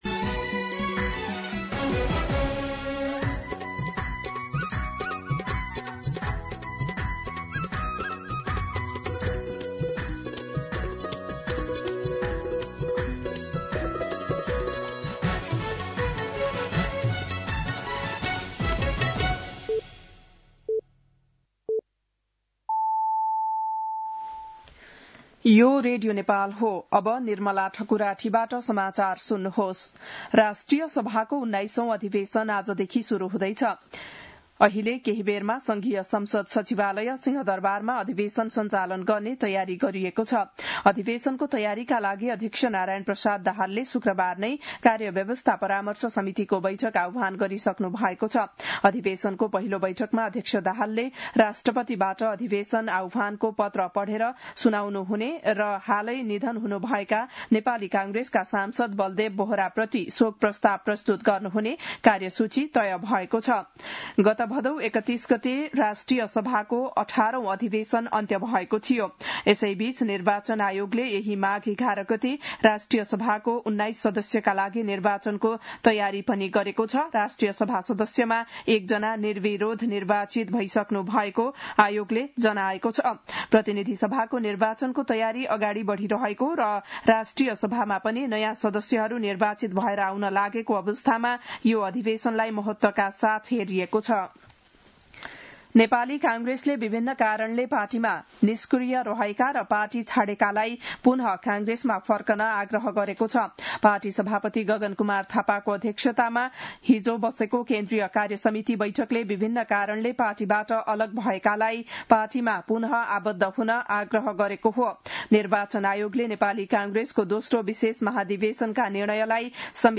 बिहान ११ बजेको नेपाली समाचार : ४ माघ , २०८२
11-am-News-10-4.mp3